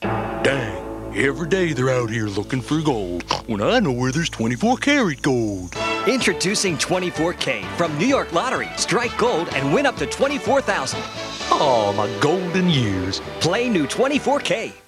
VOICE OVERS Television and Radio
TELEVISION